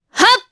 Priscilla-Vox_Attack1_jp.wav